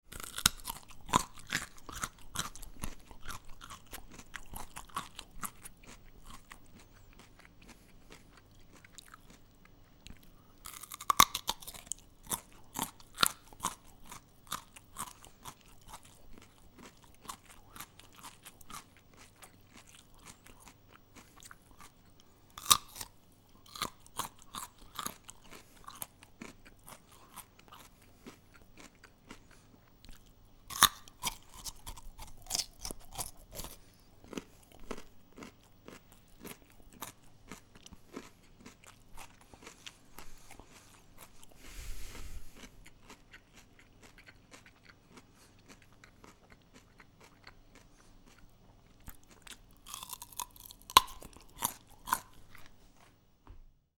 Звуки моркови